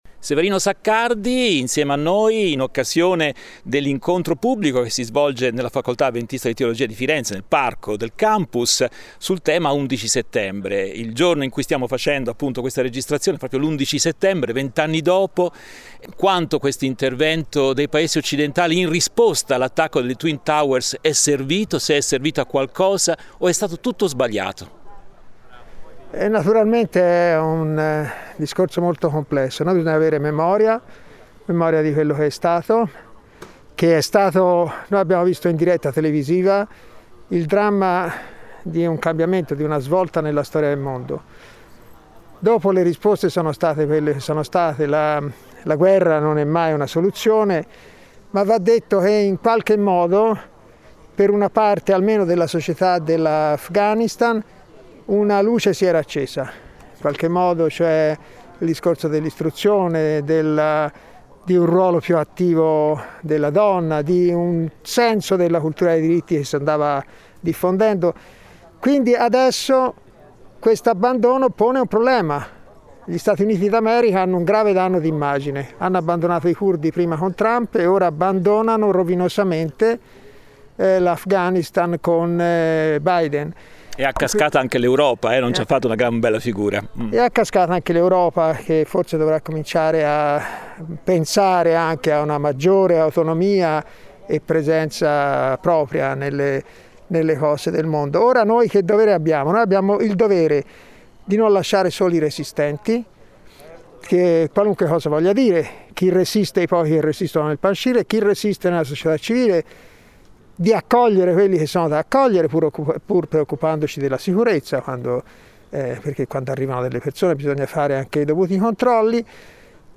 Sabato 11 settembre si è svolto nel parco storico di Villa Aurora, presso il campus della Facoltà avventista di teologia, l'incontro pubblico sul tema : 11 settembre, la data infausta.